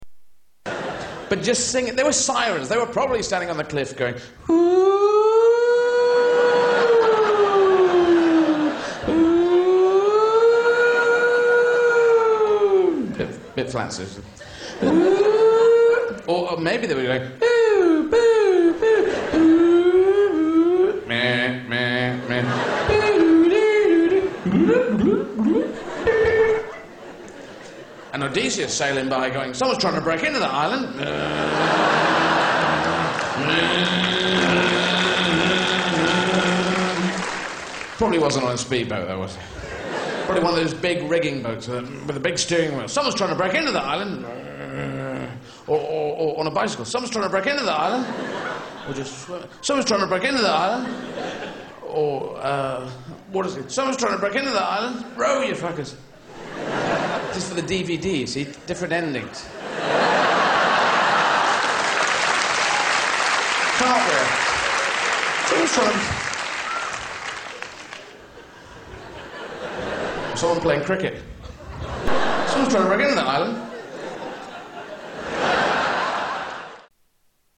Category: Comedians   Right: Personal
Tags: Comedians Eddie Izzard Eddie Izzard Soundboard Eddie Izzard Clips Stand-up Comedian